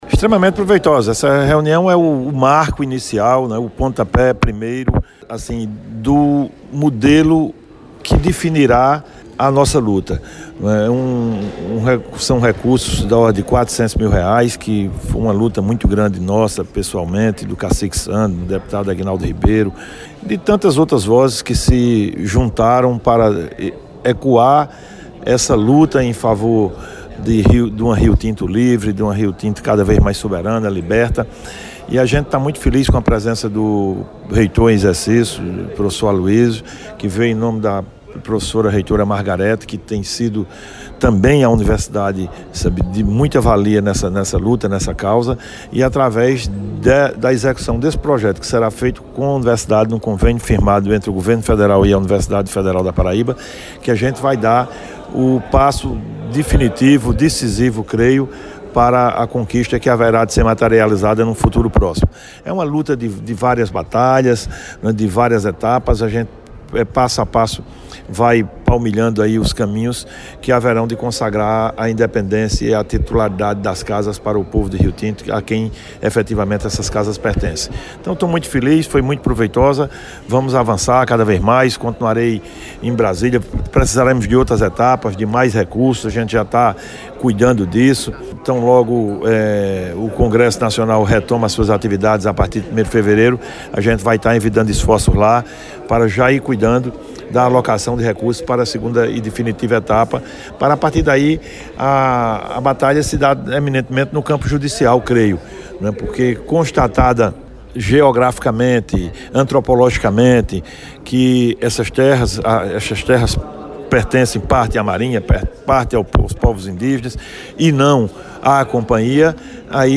O deputado estadual Ricardo Barbosa (PSB), comemorou durante entrevista ao PBVale, depois de participar de reunião do movimento ‘Liberta Rio Tinto’, no espaço de eventos Orion Show, a conquista de recursos junto ao Ministério da Educação para viabilizar trabalho de pesquisa envolvendo geoprocessamento das terras de Rio Tinto.